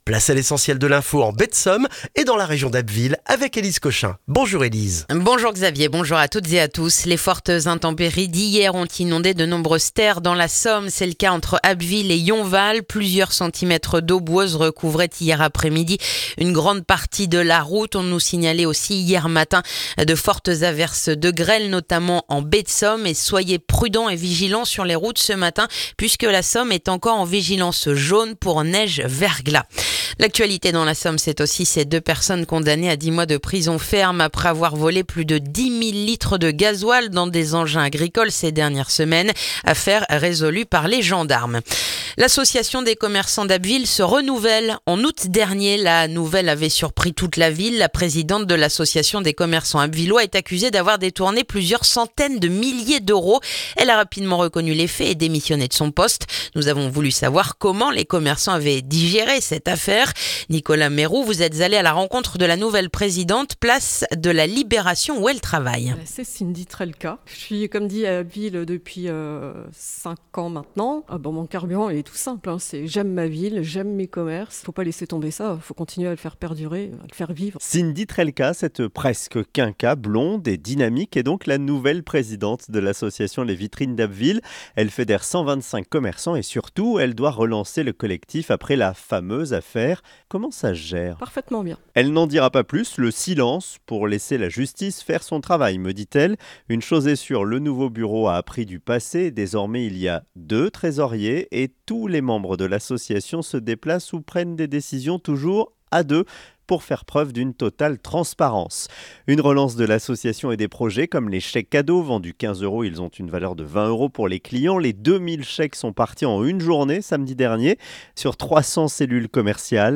Le journal du jeudi 20 novembre en Baie de Somme et dans la région d'Abbeville